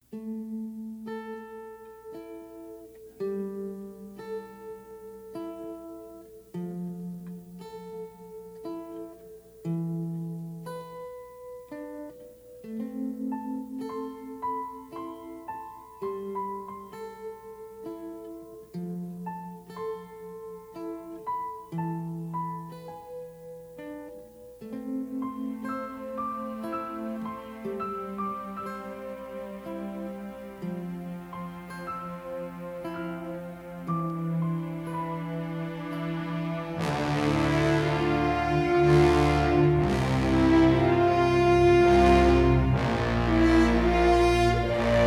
acoustic guitar
remixed and mastered from the original 1/2" stereo tapes.